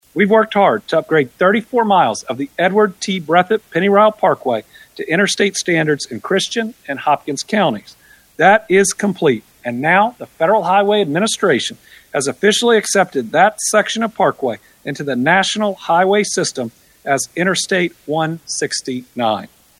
During his weekly “Team Kentucky” update Thursday, Governor Andy Beshear confirmed critical news about the Ned Breathitt Parkway gaining a considerable designation.